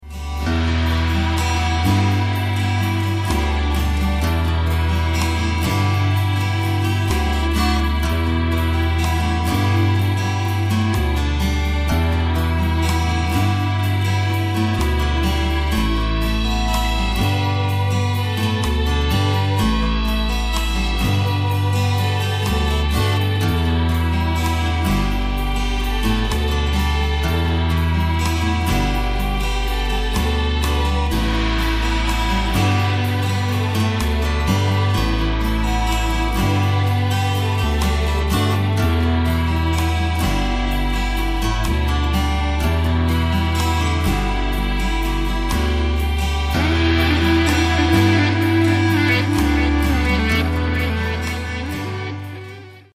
intense category-defying instrumentals